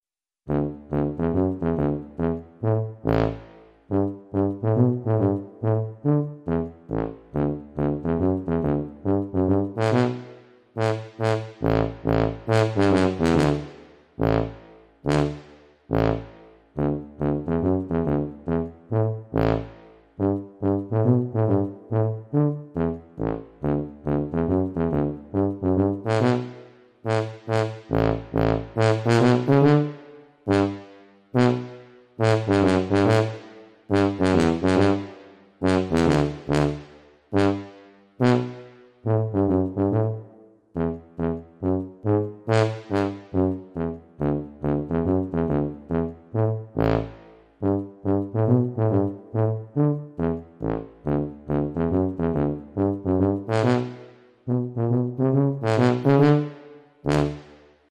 این ساز در دسته سازهای کلاسیک میباشد که صدایی بسیار بم دارد.
ساز توبا را به نوعی بم ترین ساز خانواده سازهای برنجی مینامند.
صدای ساز توبا:
صدای-ساز-توبا.mp3